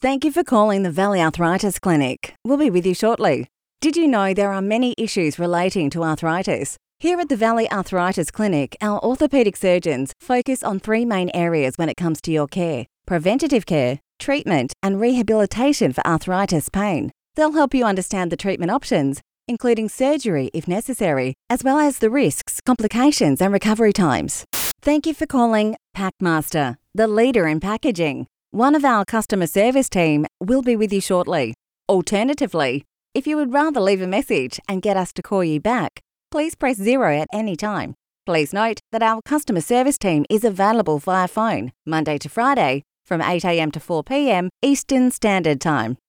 • On Hold
• Versatile